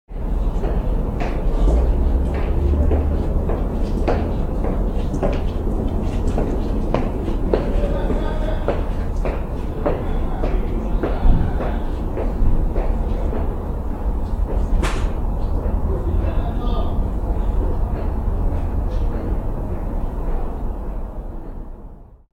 دانلود صدای اتوبوس 9 از ساعد نیوز با لینک مستقیم و کیفیت بالا
جلوه های صوتی
برچسب: دانلود آهنگ های افکت صوتی حمل و نقل دانلود آلبوم صدای اتوبوس از افکت صوتی حمل و نقل